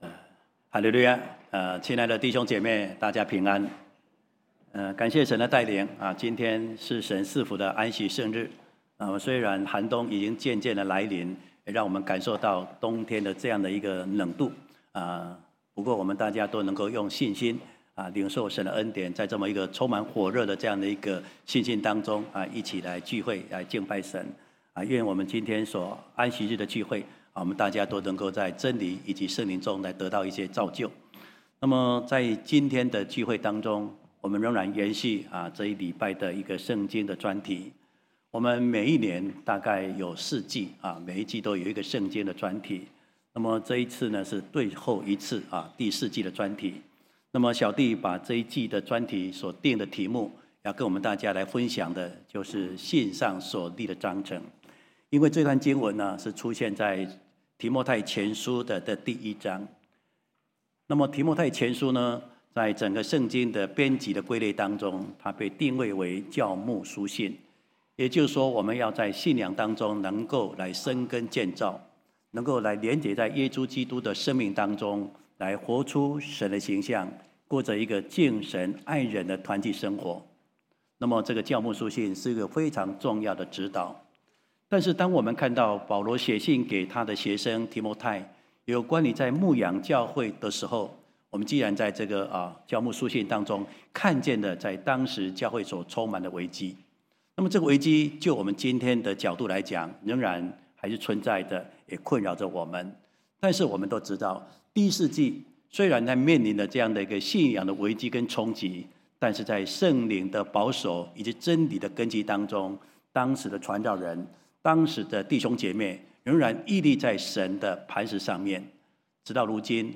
聖經專題：信上所立的章程（三）-講道錄音